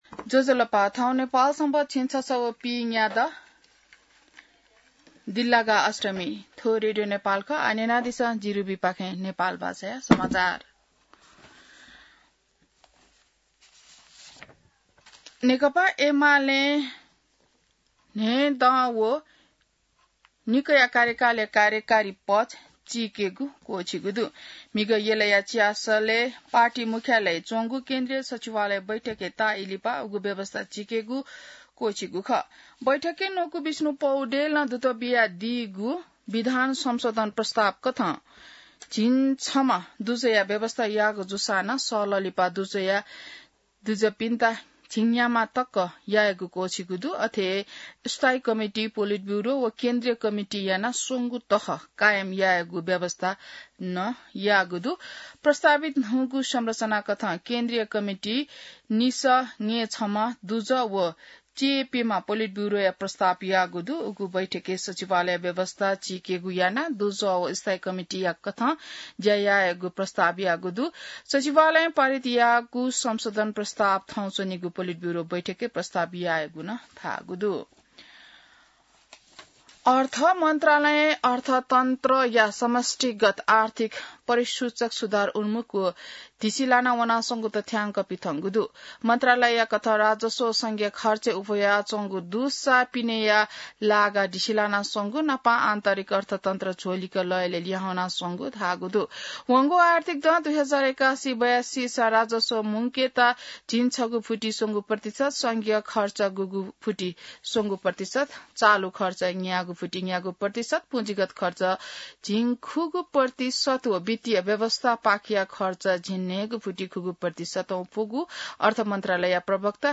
नेपाल भाषामा समाचार : २ साउन , २०८२